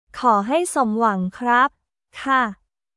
コー ハイ ソムワン クラップ/カー